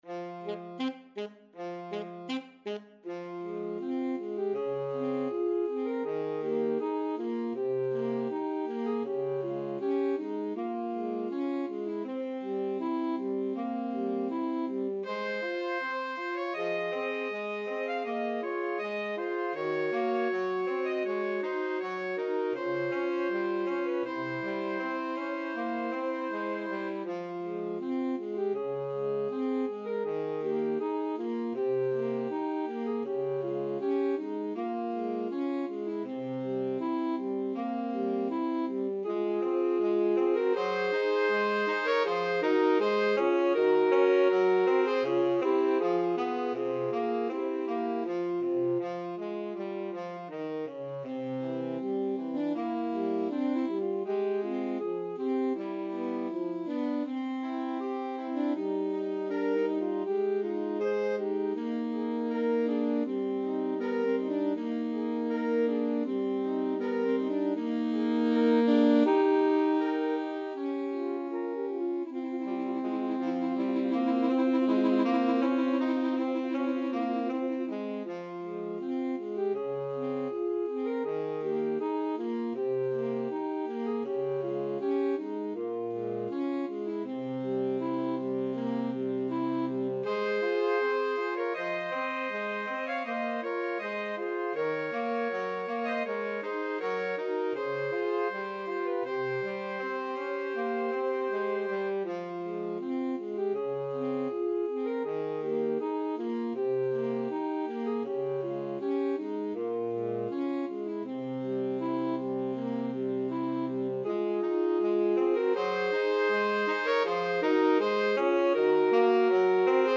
Alto Saxophone 1Alto Saxophone 2Tenor Saxophone
Andante
Classical (View more Classical 2-Altos-Tenor-Sax Music)